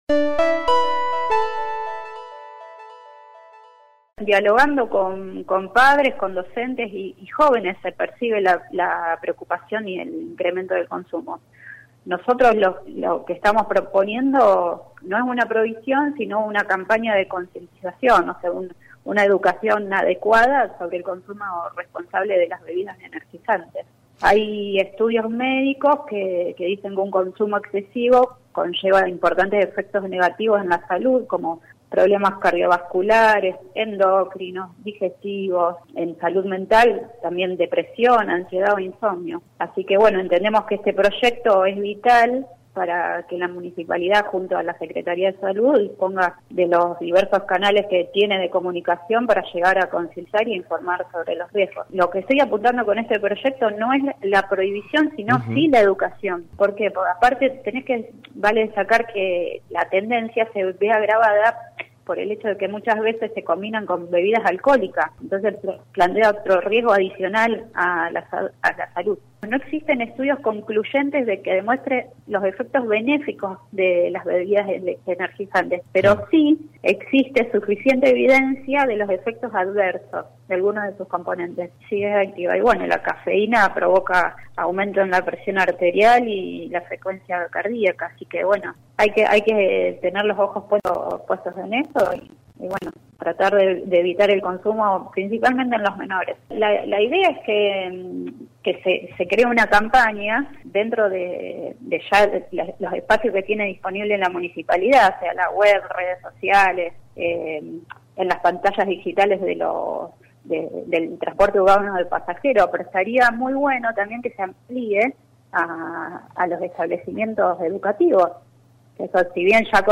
La concejala de la ciudad de Rosario Marisol Bracco en contacto con LT3 se refirió a la campaña que viene impulsando en relación a la campaña de concientización y educación adecuada sobre el consumo responsable de las bebidas energizantes.